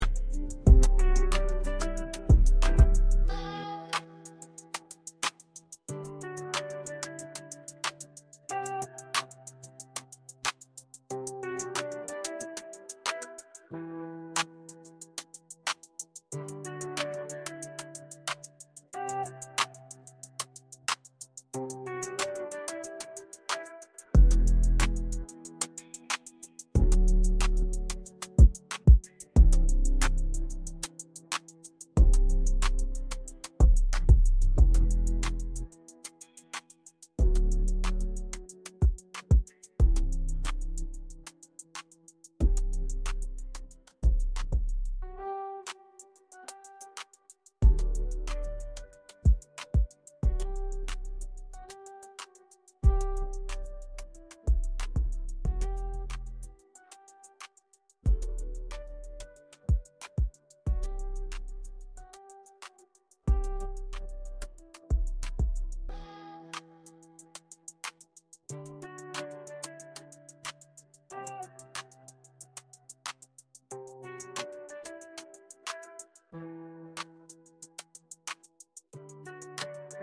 Ai Generated -Free To use for anyone!